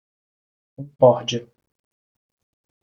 Pronunciato come (IPA)
/kõˈkɔʁ.d͡ʒi.ɐ/